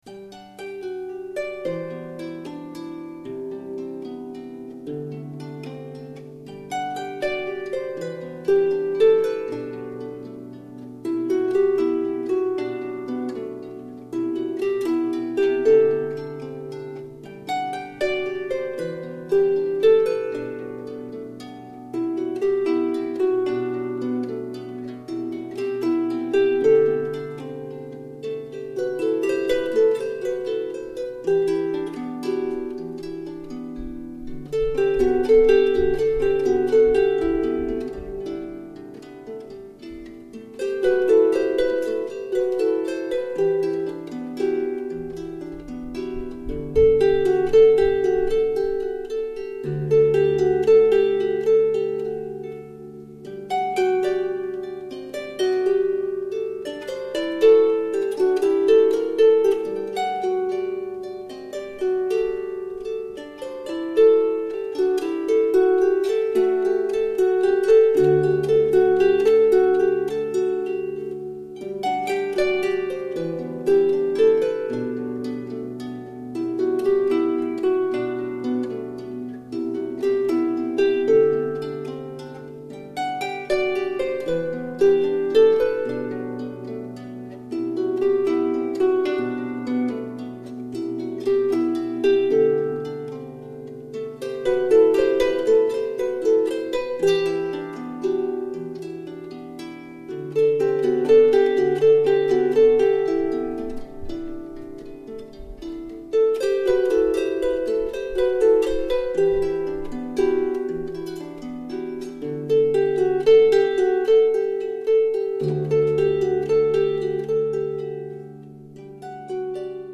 EN CONCERT A L' ABBAYE DE CAUNES MINERVOIS LE 24 FEV.2006
HARPE CELTIQUE